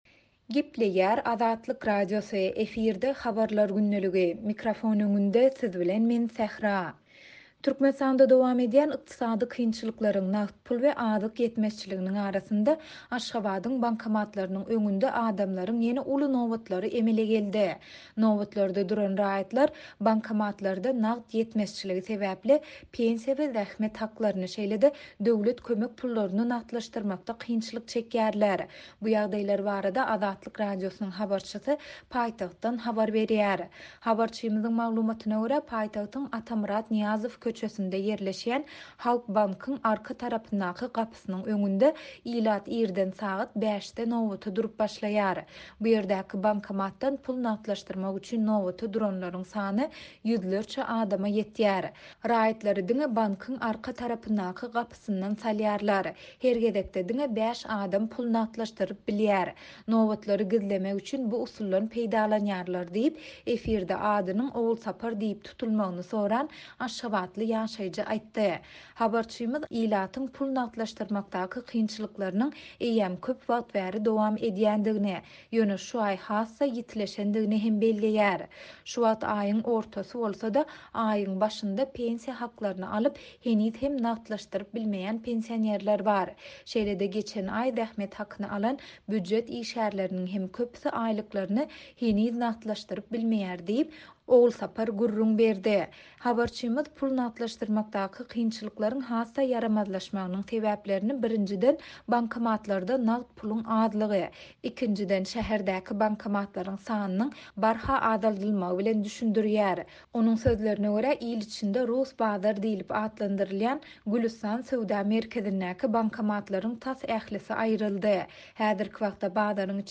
Türkmenistanda dowam edýän ykdysady kynçylyklaryň, nagt pul we azyk ýetmezçiliginiň arasynda, Aşgabadyň bankomatlarynyň öňünde adamlaryň ýene uly nobatlary emele geldi. Nobatlarda duran raýatlar, bankomatlarda nagt ýetmezçiligi sebäpli, pensiýa we zähmet haklaryny, şeýle-de döwlet kömek pullaryny nagtlaşdyrmakda kynçylyk çekýärler. Bu ýagdaýlar barada Azatlyk Radiosynyň habarçysy paýtagtdan habar berýär.